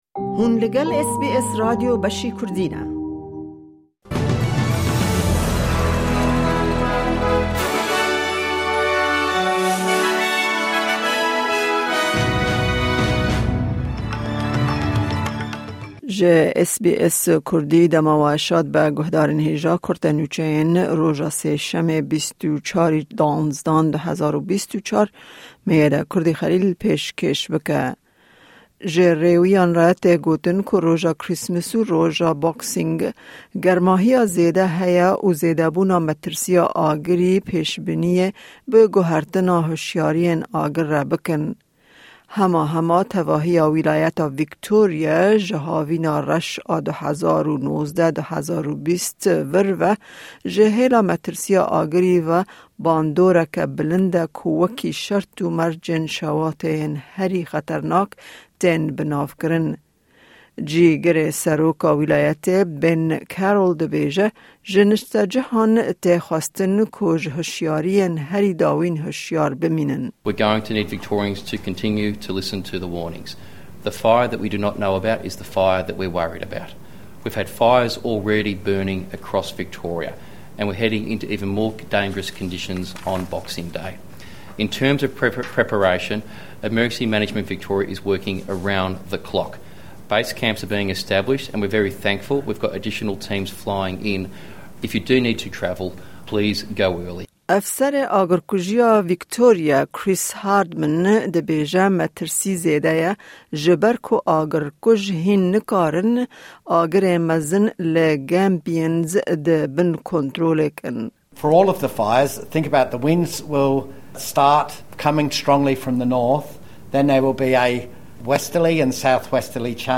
Di bûletena nûçeyên îro de: Ji Rêwiyan re hişyarî hat dayin ku di 48 demjimêrên li pêş de ji xetereya agirêli seranserê Australya hişyar bin Bandora 50 saliya Cyclone Tracy ya li ser Darwin, ew nûçeyana û nûçeyên din di bûletenê de hene.